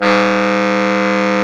Index of /90_sSampleCDs/Roland L-CD702/VOL-2/SAX_Baritone Sax/SAX_Baritone FX